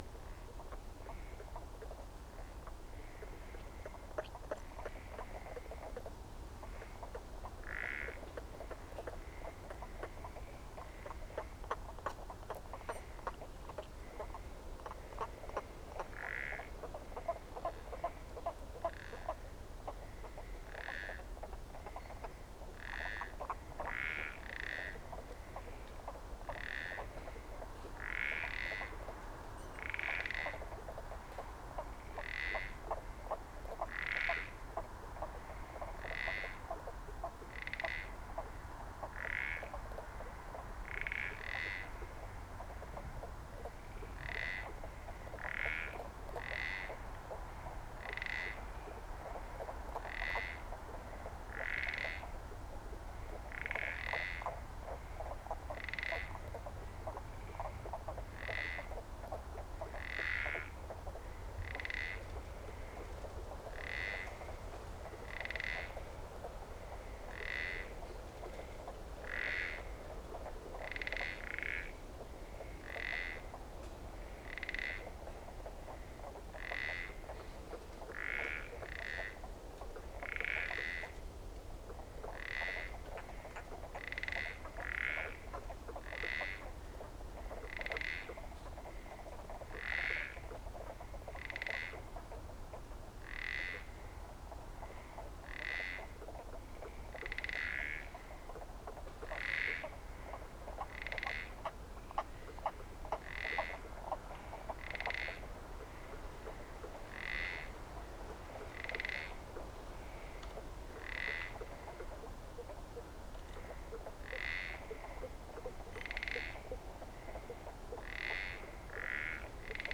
Carte d’identité du Pélobates cultripède
Chant : Un « kô-kô-kô » sourd et étouffé, rappelant le gloussement d’une poule, à écouter dans l’audio ci-dessous